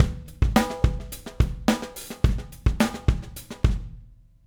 Retro Funkish Beat Intro 01.wav